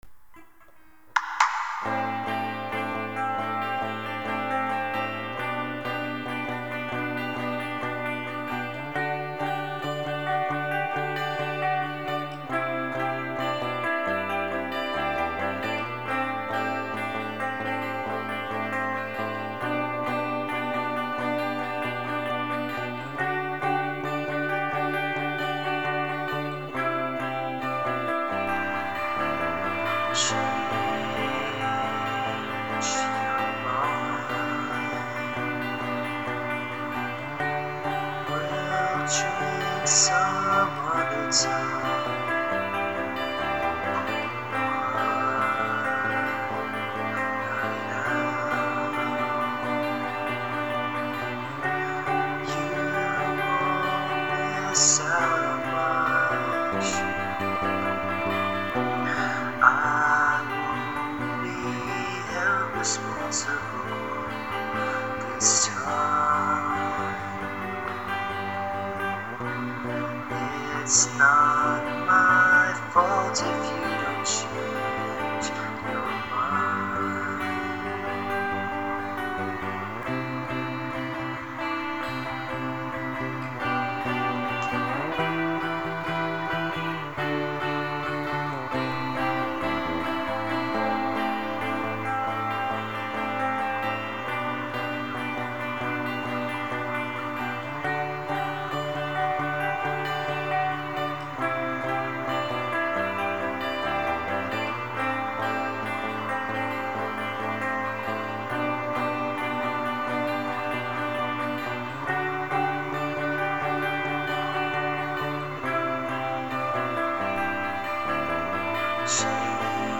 stripped-back fan cover